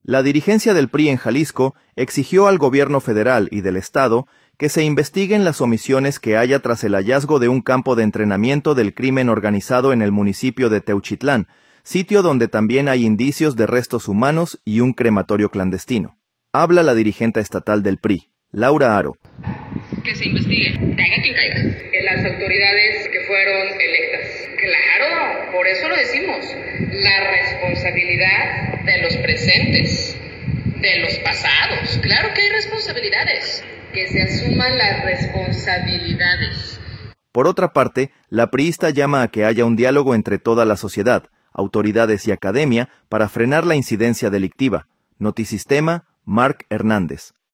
audio La dirigencia del PRI en Jalisco exigió al Gobierno Federal y del Estado que se investiguen las omisiones que haya tras el hallazgo de un campo de entrenamiento del crimen organizado en el municipio de Teuchitlán, sitio donde también hay indicios de restos humanos y un crematorio clandestino. Habla la dirigenta estatal del PRI, Laura Haro.